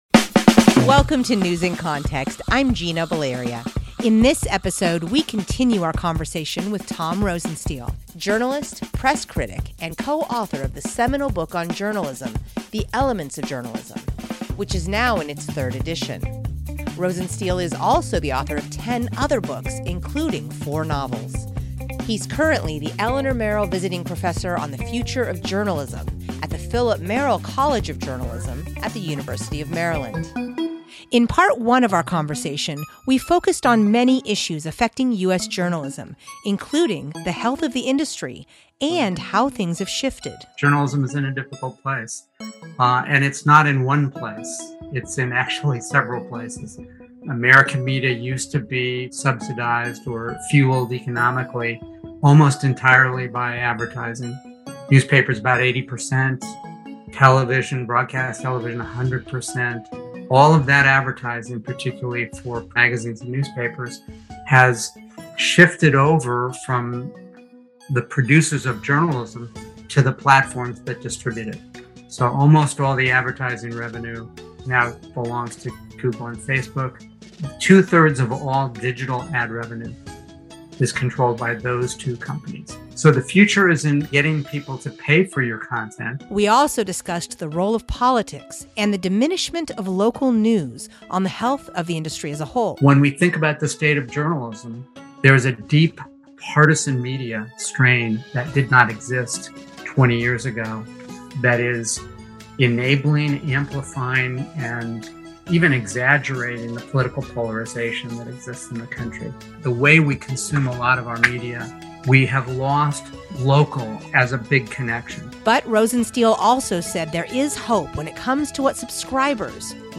In this episode, we continue our conversation with Tom Rosenstiel, journalist, press critic, and co-author of the seminal book on Journalism, The Elements of Journalism, which is now in its third edition.